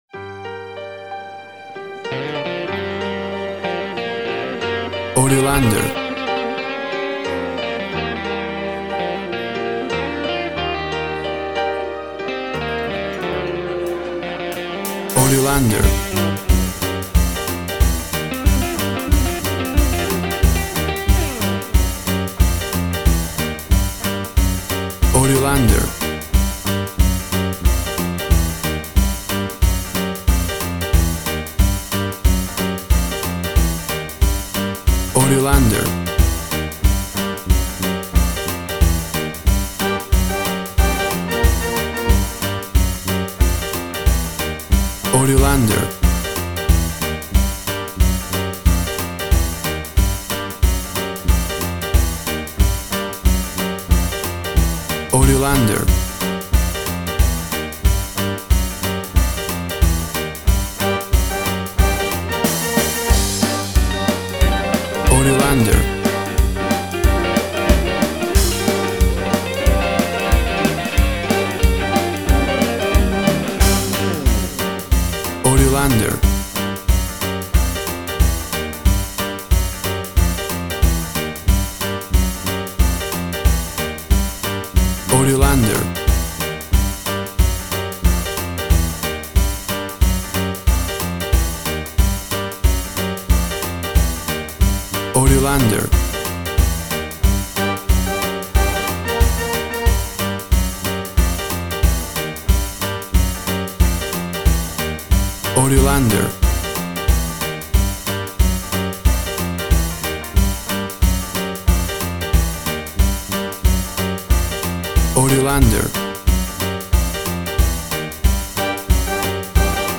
Tempo (BPM) 180